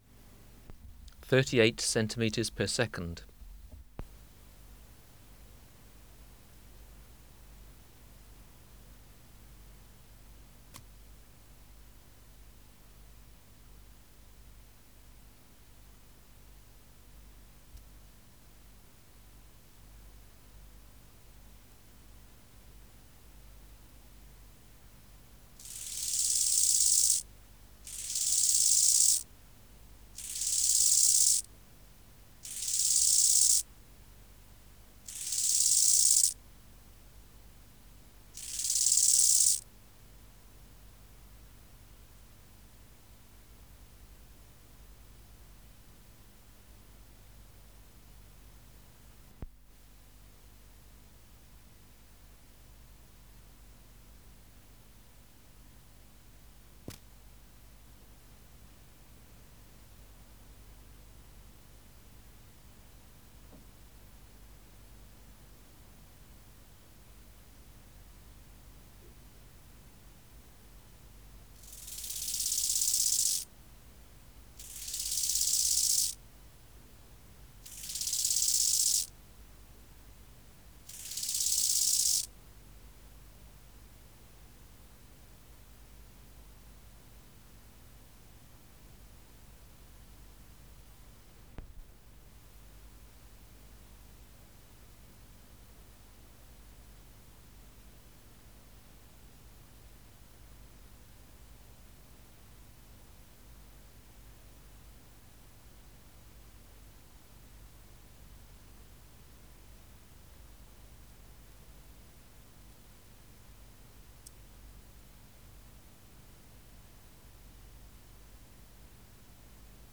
434:12 Chorthippus biguttulus (690b) | BioAcoustica
Species: Chorthippus (Glyptobothrus) biguttulus
Recording Location: BMNH Acoustic Laboratory
Reference Signal: 1 kHz for 10 s
Substrate/Cage: Small recording cage Biotic Factors / Experimental Conditions: Isolated male
Microphone & Power Supply: Sennheiser MKH 405 Filter: Low Pass, 24 dB per octave. Corner frequency 20 Hz